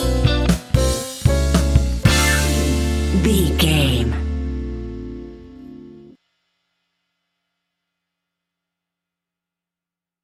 Ionian/Major
D
house
synths
techno
trance
instrumentals